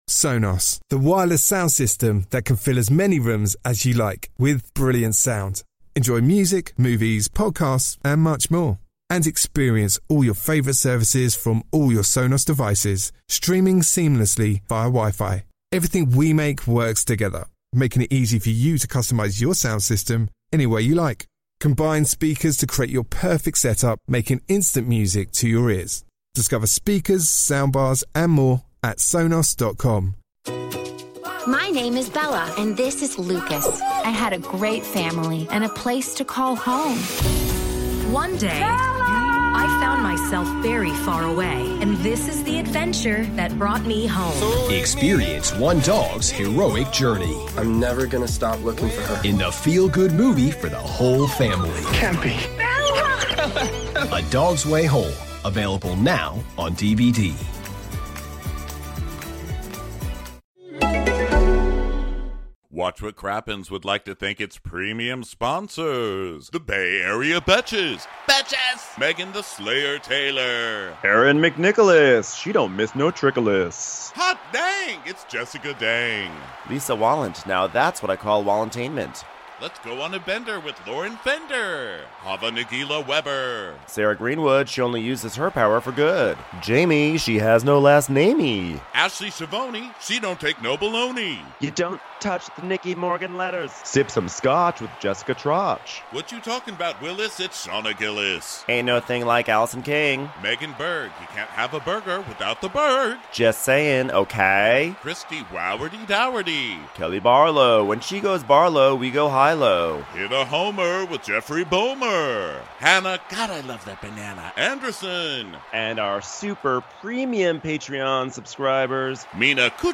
We're live in Minneapolis to laugh about the Real Housewives of New York trip to Miami. Can Ramona bag a new guy while dangling her ex husband as bait?